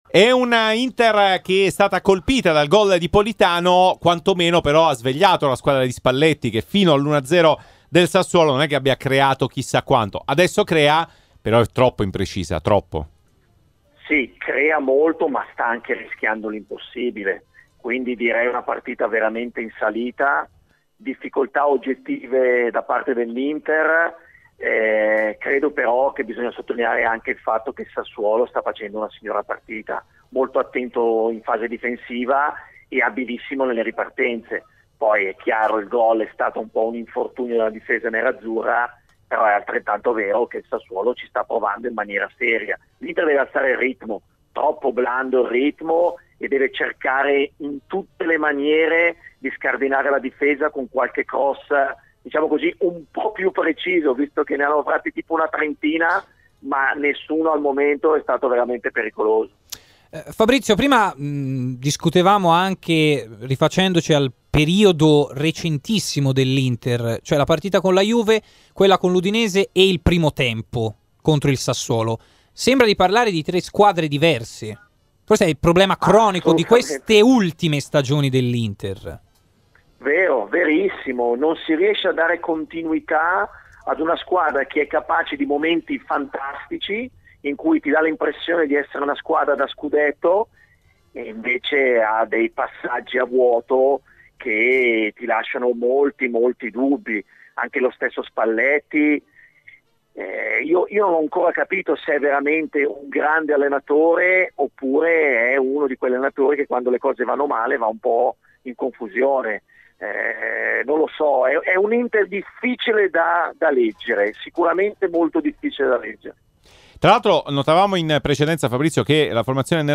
durante il Live Show di RMC Sport ha parlato di Candreva